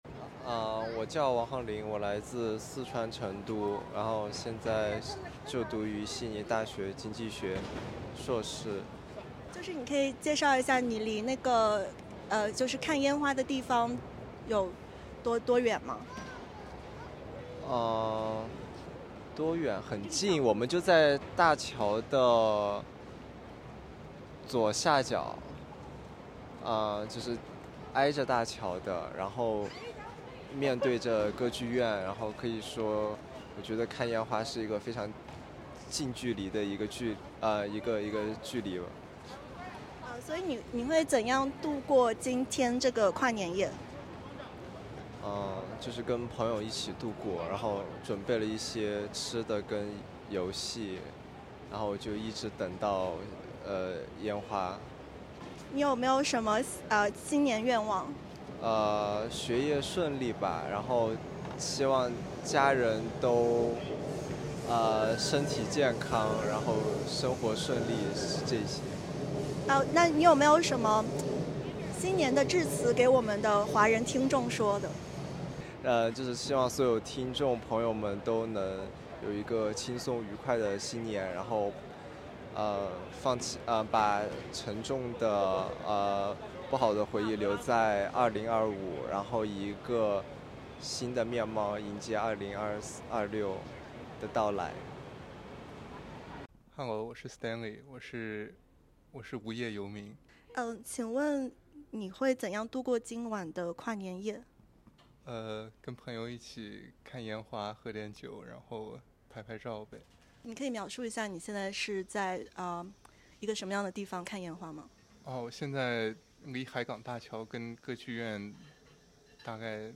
今晚数万人涌向悉尼港，期待年度最大烟花秀（点击音频，收听报道）。
来自中国、新加坡等地的华人告诉 SBS记者，他们准备了食物和桌游，在现场等上超过八个小时，就为近距离欣赏烟花点亮港湾的那一刻。